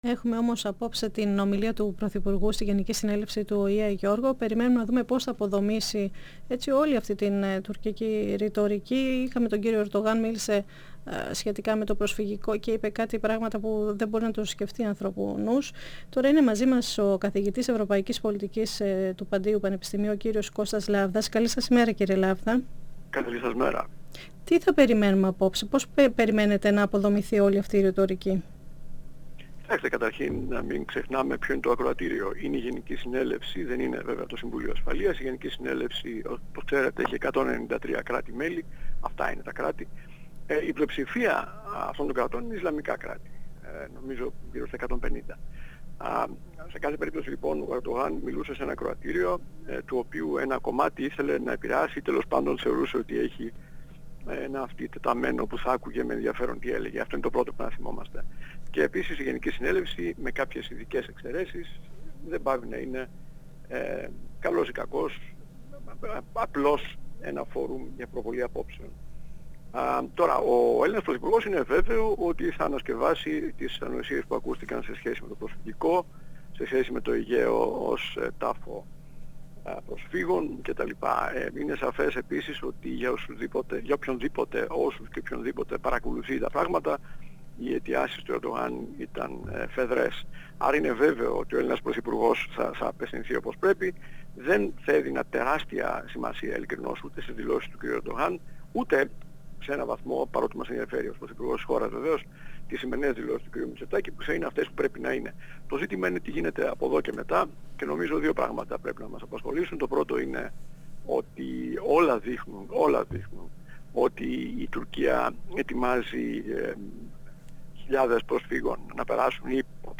Αναφερόμενος στις κυρώσεις της Ευρωπαϊκής Ένωσης στη Ρωσία λόγω της εισβολής στην Ουκρανία, τόνισε ήταν μια βιαστική προσέγγιση, που έπρεπε να γίνει κλιμακωτά. 102FM Συνεντεύξεις ΕΡΤ3